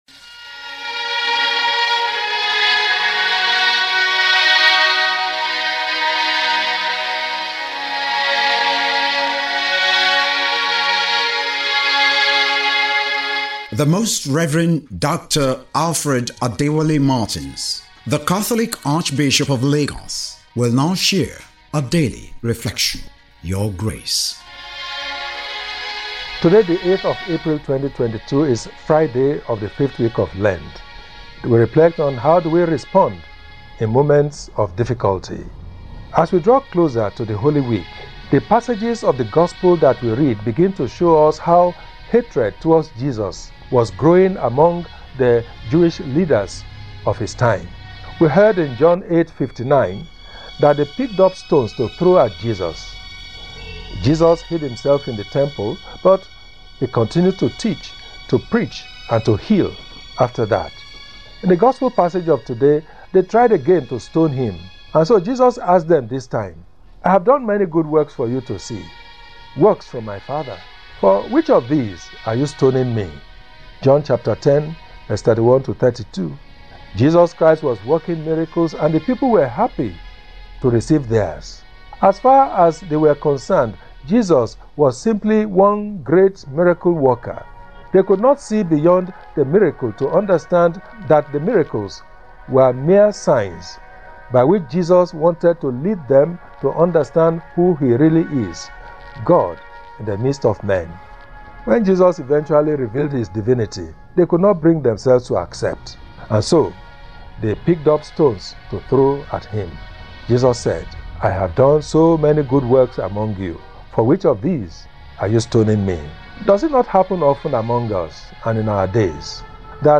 LENTEN REFLECTION WITH ARCHBISHOP ALFRED MARTINS.
FRIDAY-8-LENTEN-TALK.mp3